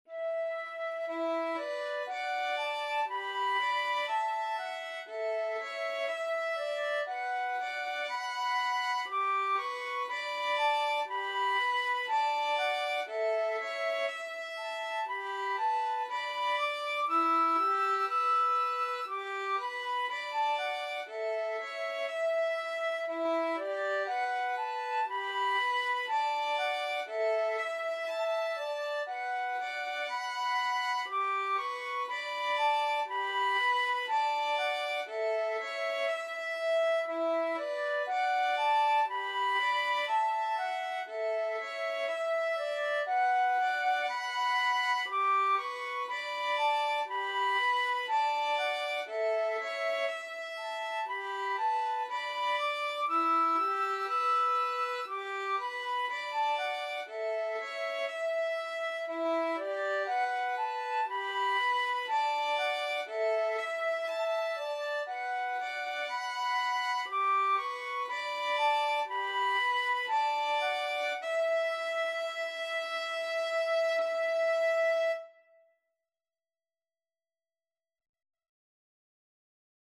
2/2 (View more 2/2 Music)
Andante Cantabile = c. 60
Violin-Flute Duet  (View more Easy Violin-Flute Duet Music)
Classical (View more Classical Violin-Flute Duet Music)